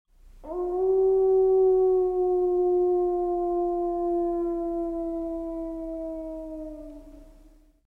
Wolf Howl Téléchargement d'Effet Sonore
Wolf Howl Bouton sonore